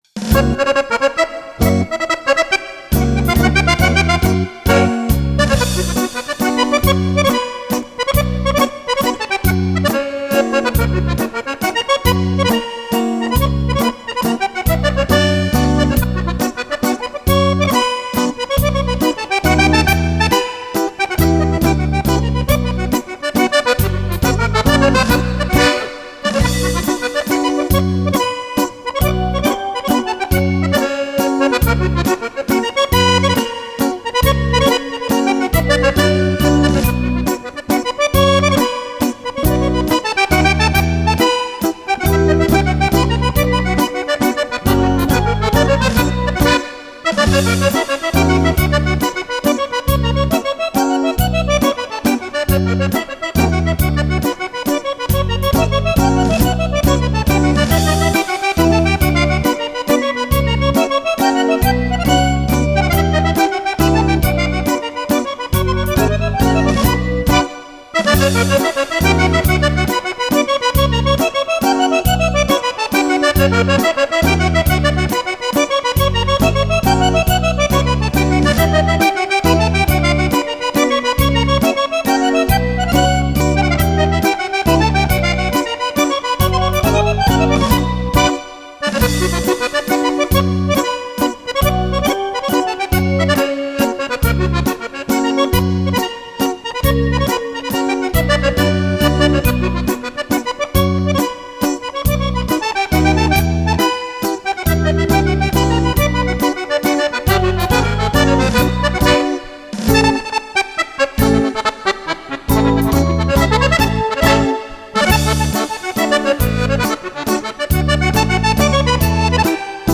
Mazurka
14 ballabili per fisarmonica solista
Registrato in Home Recording